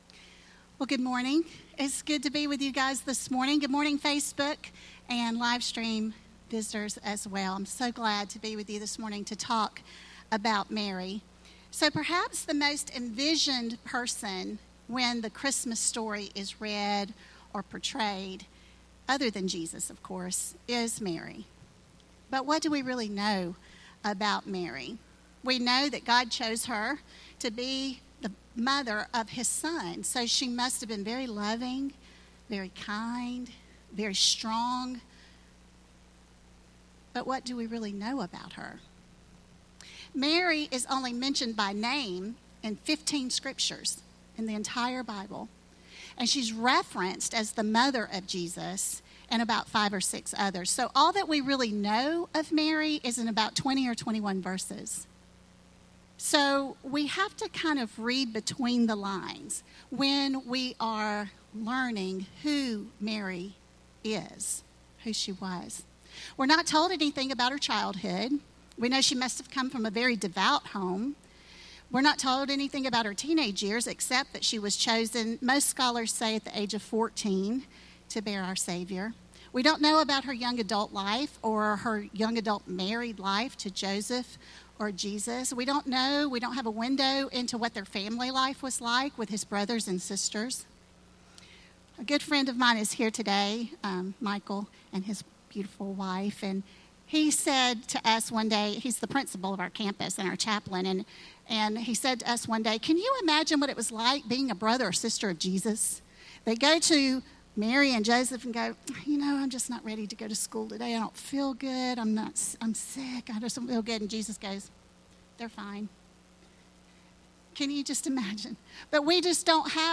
More Messages from Guest Speaker | Download Audio